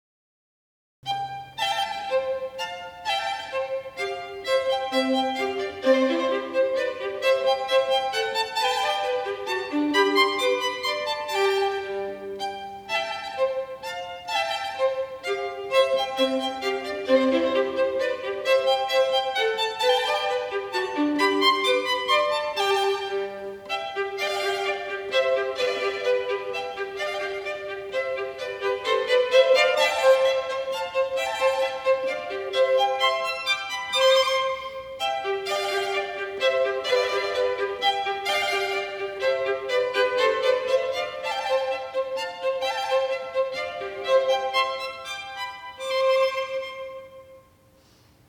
Scherzando. Allegretto (Trio)